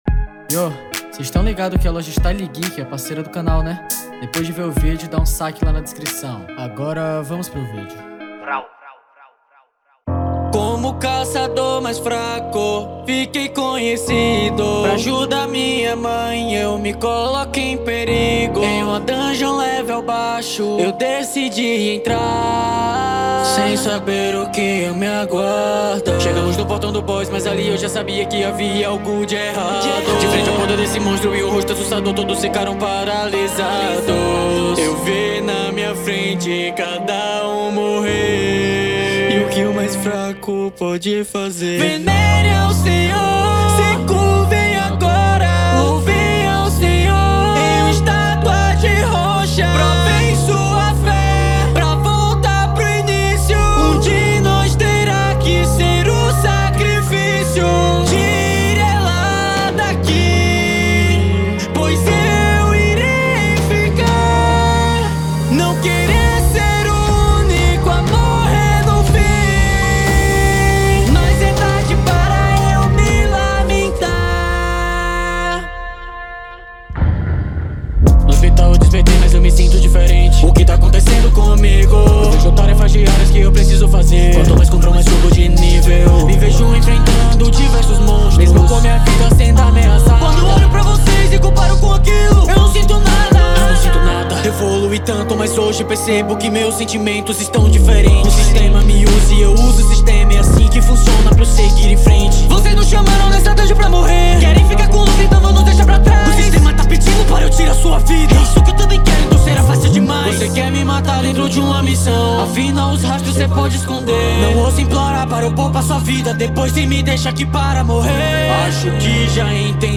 2025-02-23 15:36:04 Gênero: Rap Views